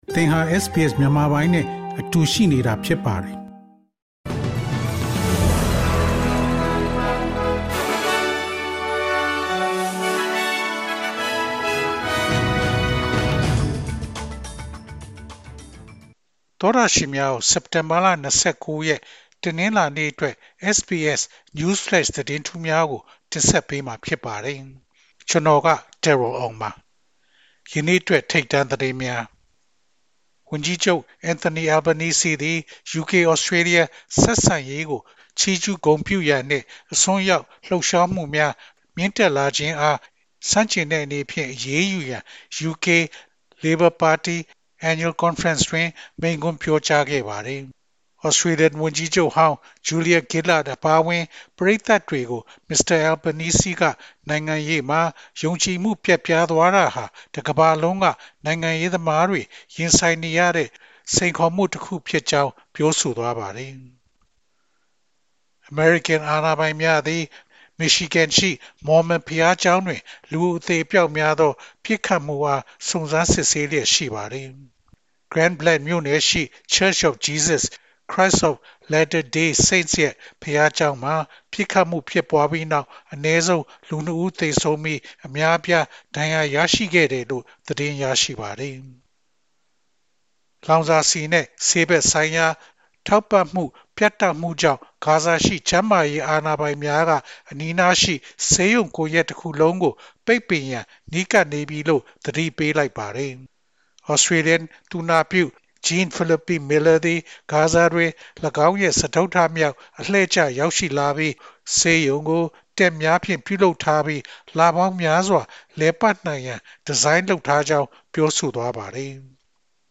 ALC: ၂၀၂၅ ခုနှစ် စက်တင်ဘာလ ၂၉ ရက်, SBS Burmese News Flash သတင်းများ။